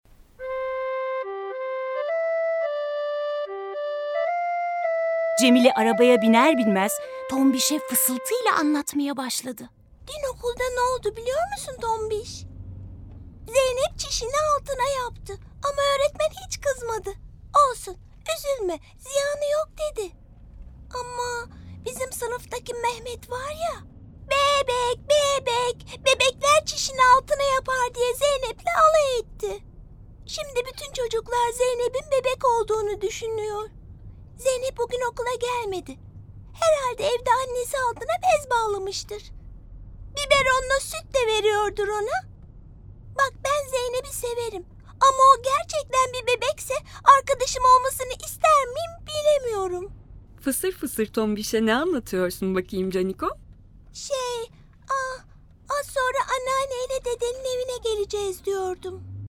Cemile (Çişini Altına Yapıyor) Tiyatrosu